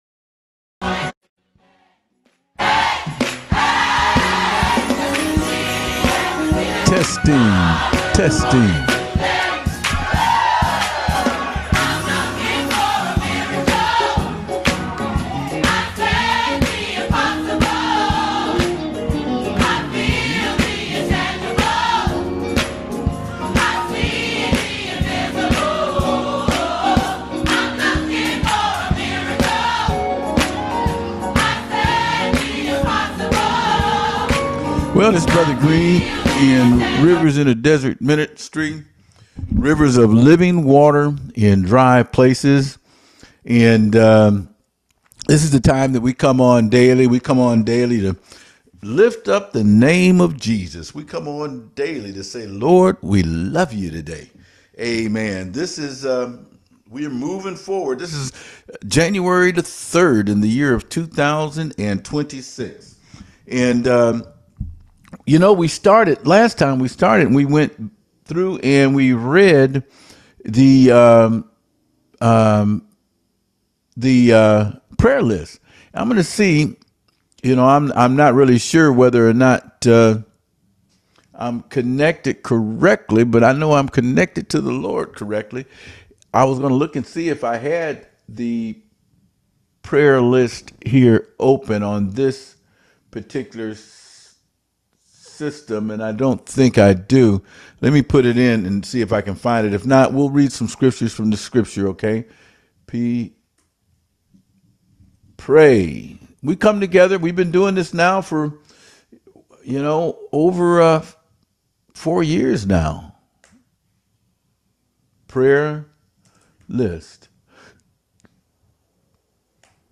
Daily Prayer Group – River in a Desert Ministry